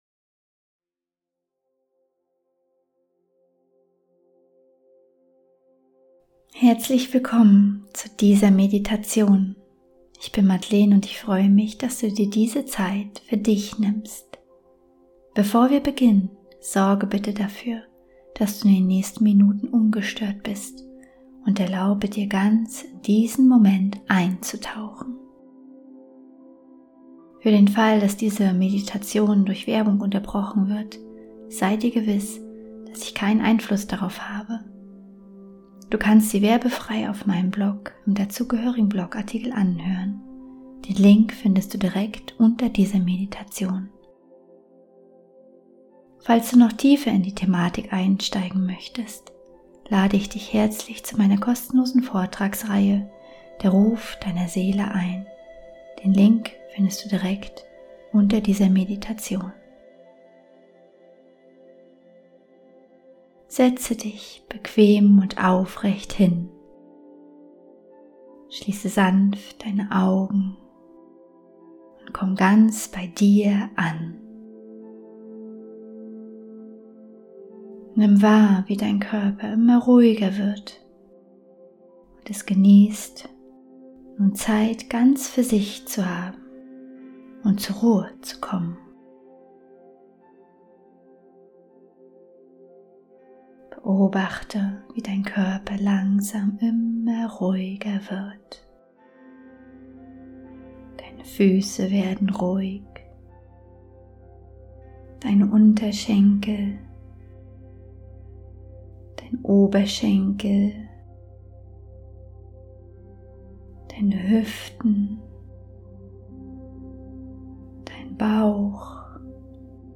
12 Minuten geführte Meditation: Von Selbstzweifeln zu deinem einzigartigen Weg ~ Heimwärts - Meditationen vom Funktionieren zum Leben Podcast